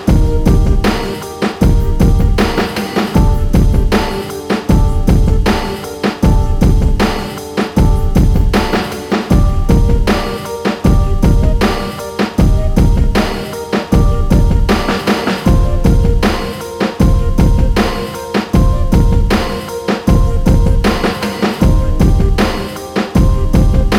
No Backing Vocals R'n'B / Hip Hop 4:46 Buy £1.50